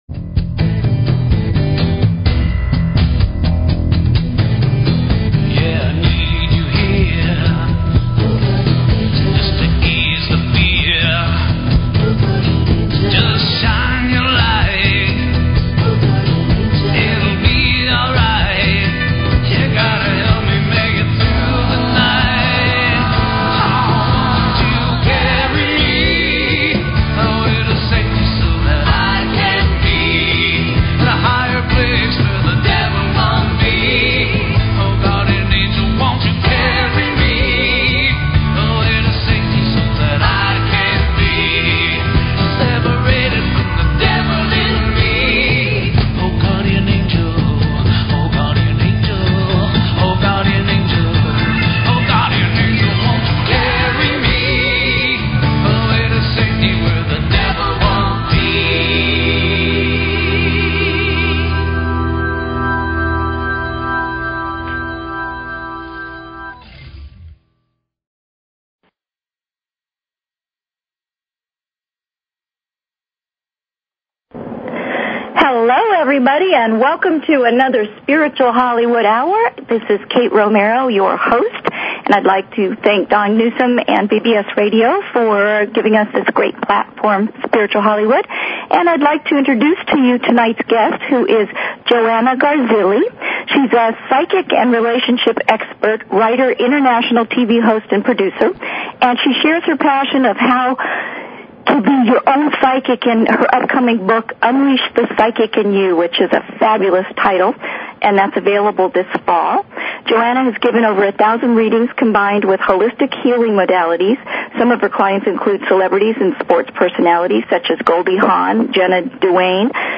Talk Show Episode, Audio Podcast, Spiritual_Hollywood and Courtesy of BBS Radio on , show guests , about , categorized as